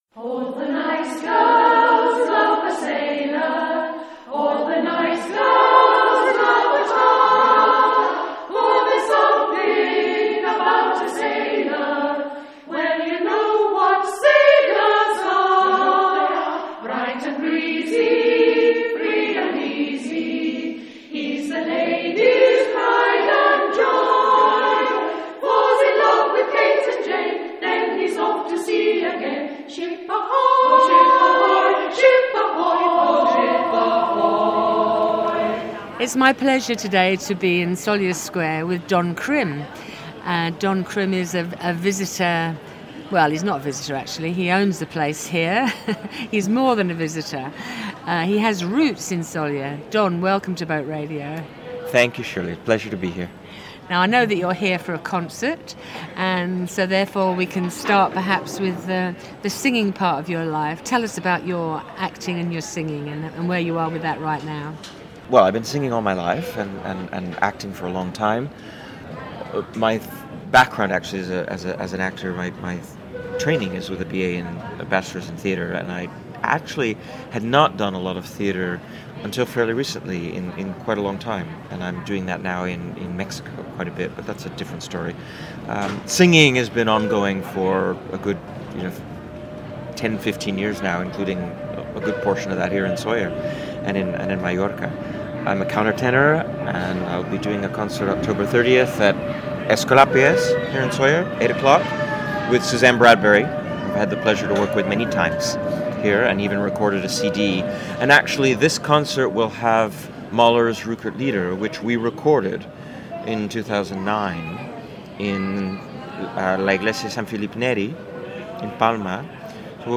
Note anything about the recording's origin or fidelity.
They met at Café Central in the very heart of Sóller. The Port of Sóller Radio Programme is a Boat Radio / Sollerweb production.